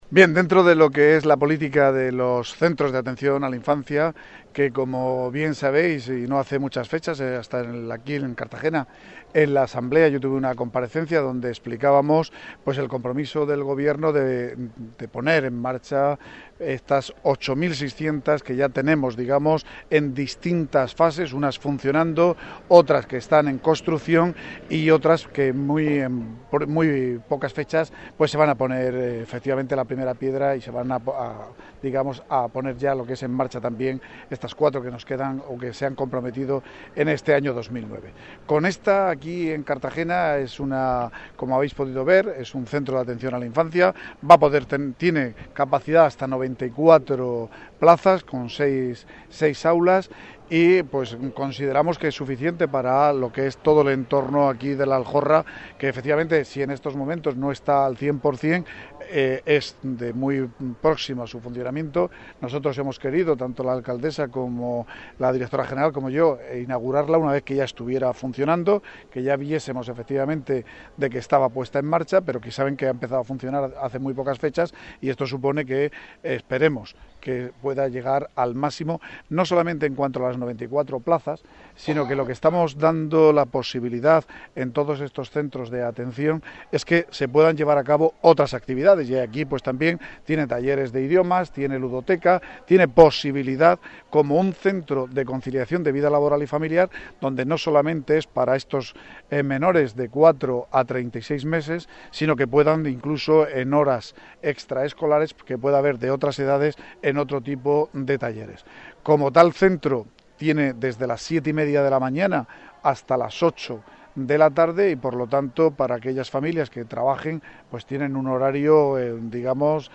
Inauguración CAI La Aljorra